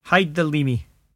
[hide the lEE-mee]